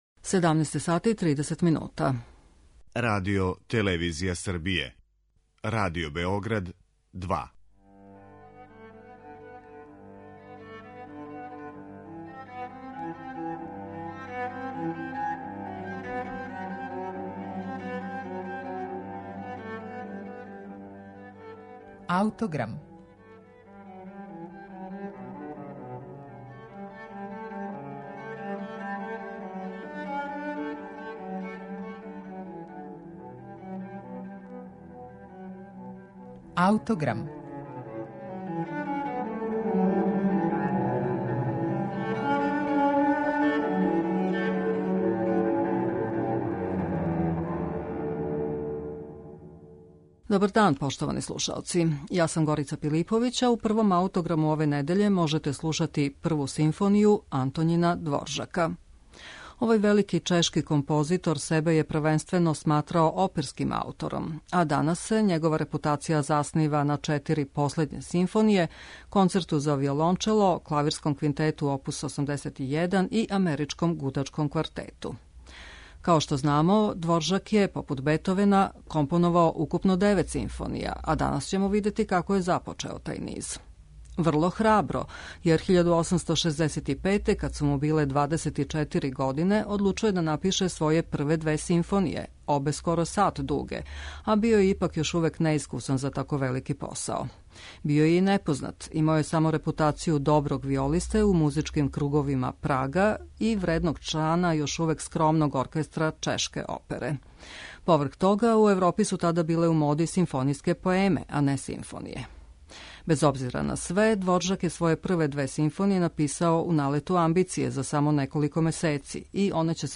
Наслов заправо није исписан у партитури, Дворжак га је касније помињао и зато још увек трају расправе да ли је дело заиста програмско. Наводно, неки пасажи у оквирним ставовима звуче као звона.